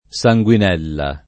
[ S a jgU in $ lla ]